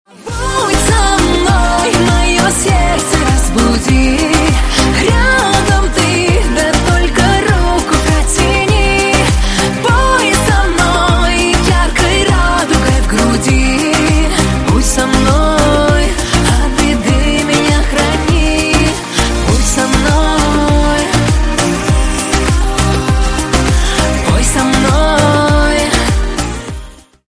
поп
русская попса
лиричные